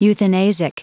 Prononciation du mot euthanasic en anglais (fichier audio)
Vous êtes ici : Cours d'anglais > Outils | Audio/Vidéo > Lire un mot à haute voix > Lire le mot euthanasic